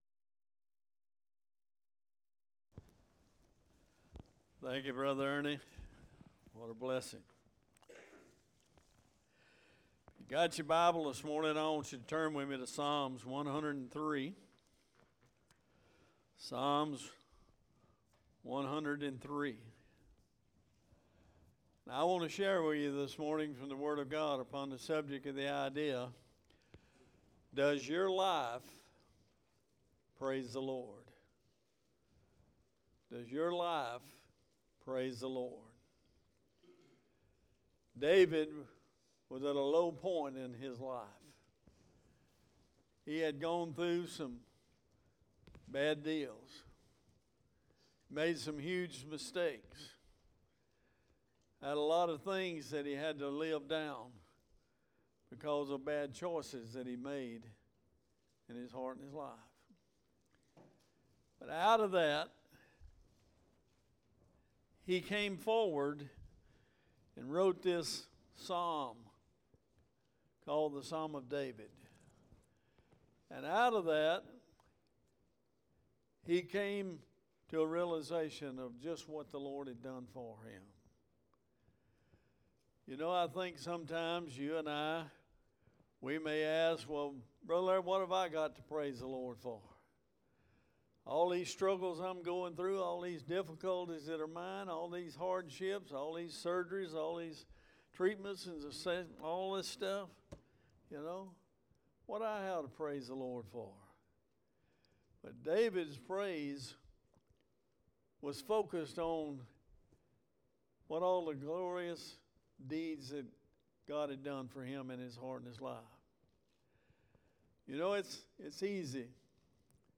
Sermons | Bexley Baptist Church